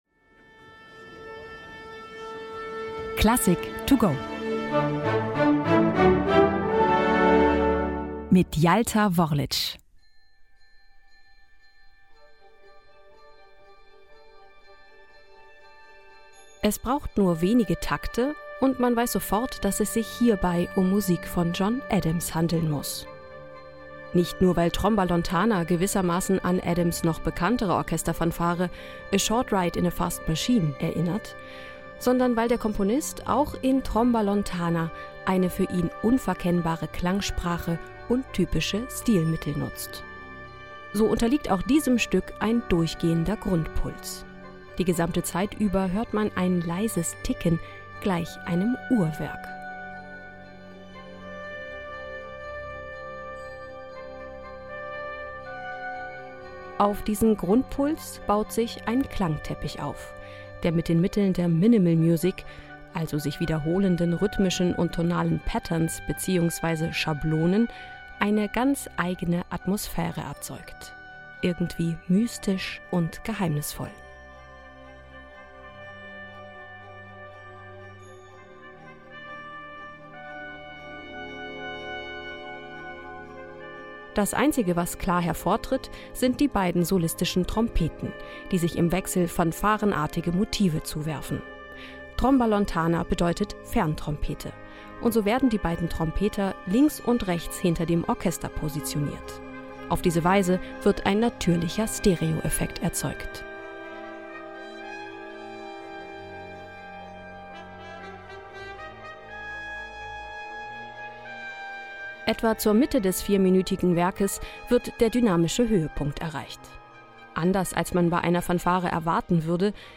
Werkeinführung für unterwegs: kurz und knapp noch vor Konzertbeginn